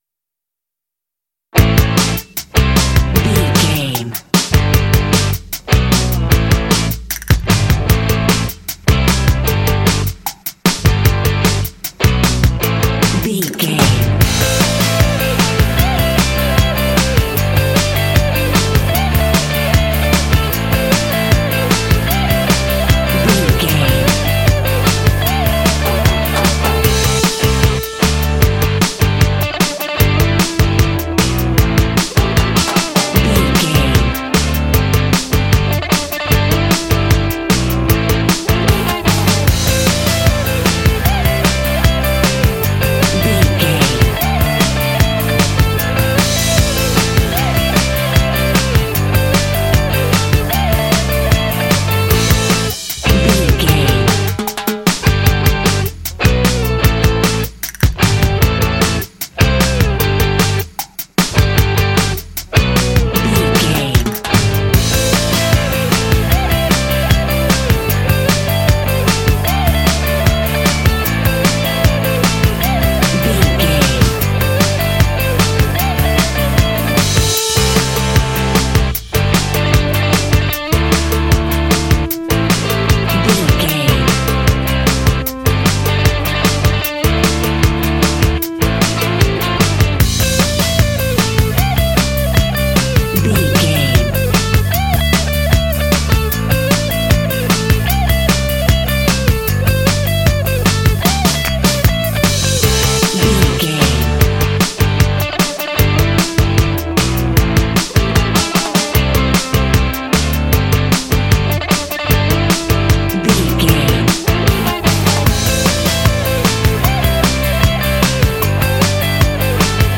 Aeolian/Minor
fun
bouncy
groovy
drums
electric guitar
bass guitar
indie